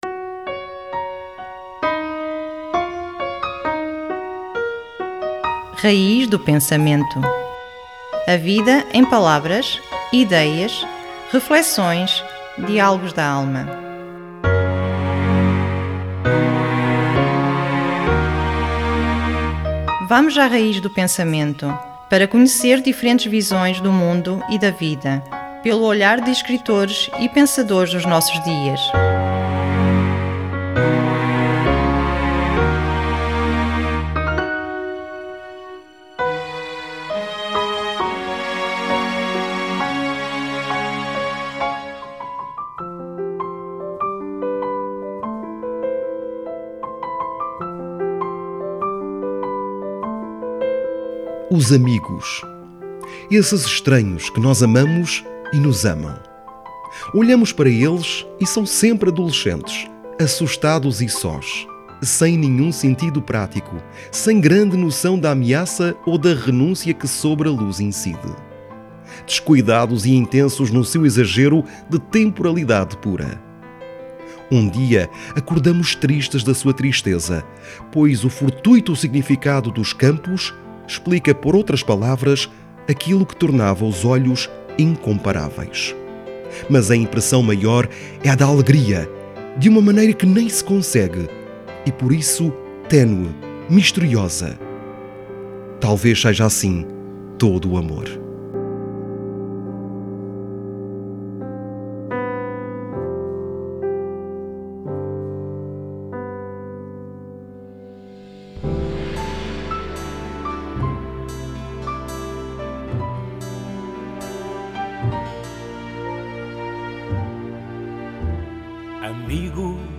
Um texto do cardeal Tolentino Mendonça trazido para a antena na rádio na rubrica "Raíz do Pensamento".